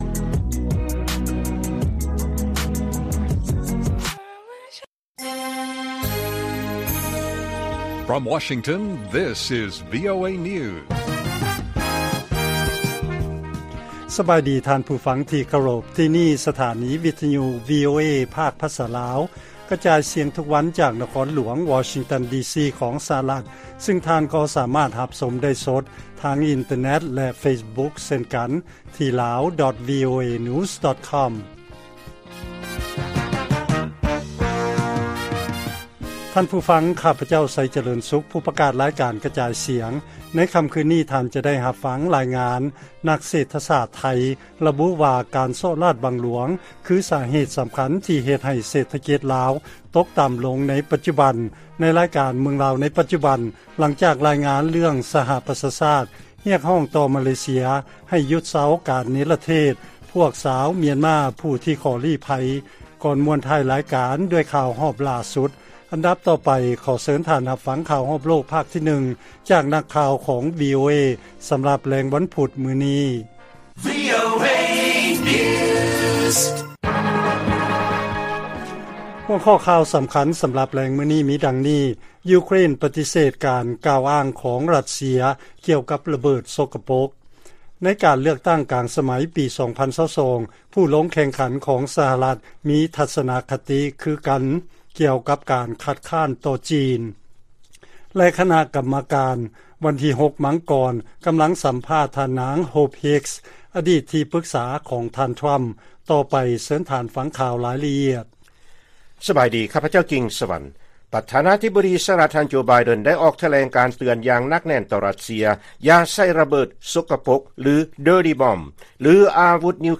ລາຍການກະຈາຍສຽງຂອງວີໂອເອ ລາວ: ປະທານາທິບໍດີ ໄບເດັນ ກ່າວວ່າ ການໃຊ້ຍຸດທະສາດ ອາວຸດນິວເຄລຍ ຂອງຣັດເຊຍ ຈະເປັນຄວາມພິດພາດທີ່ຮ້າຍແຮງ